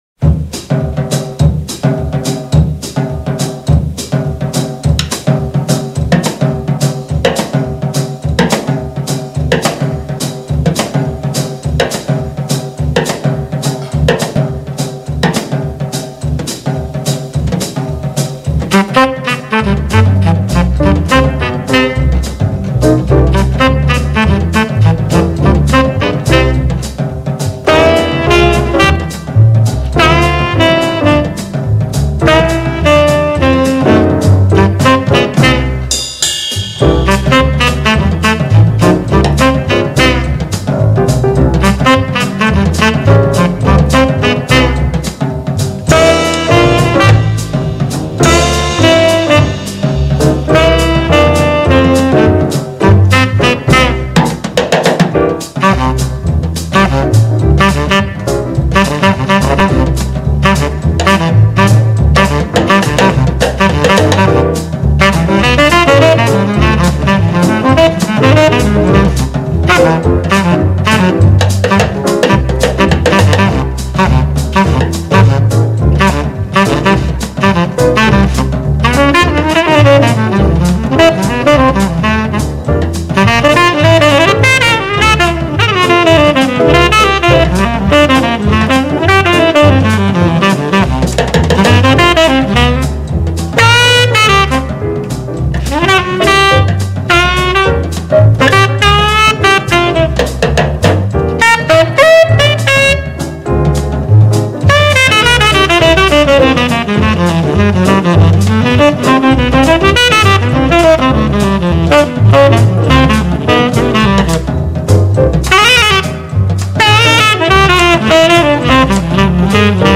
It was an album by the Jazz Tenor Saxophonist